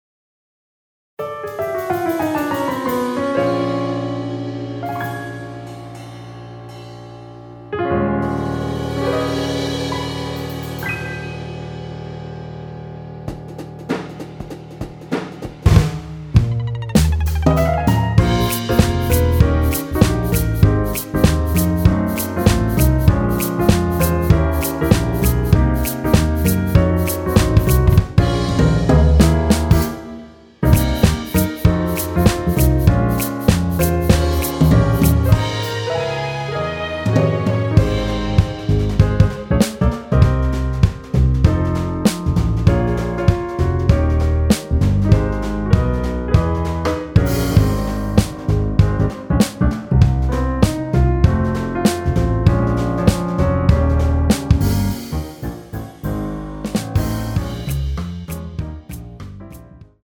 원키에서(-3)내린 MR입니다.
Ab
앞부분30초, 뒷부분30초씩 편집해서 올려 드리고 있습니다.
중간에 음이 끈어지고 다시 나오는 이유는